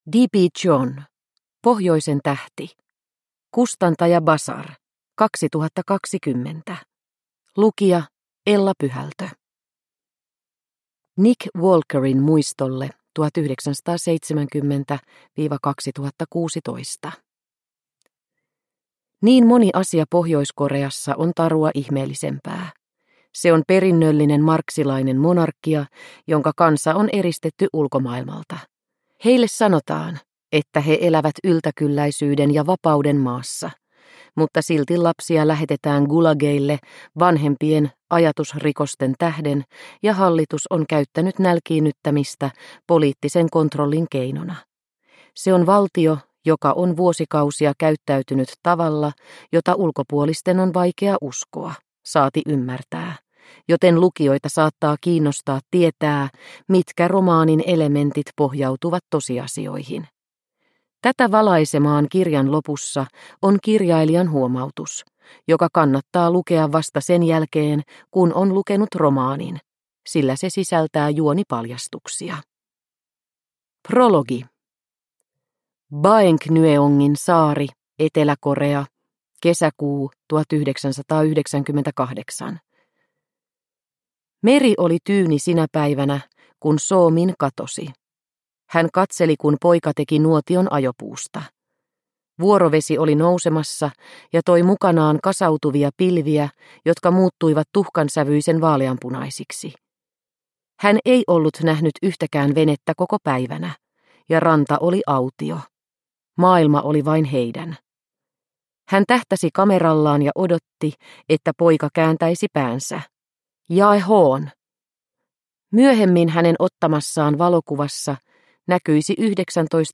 Pohjoisen tähti – Ljudbok – Laddas ner